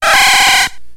Cri de Lainergie dans Pokémon X et Y.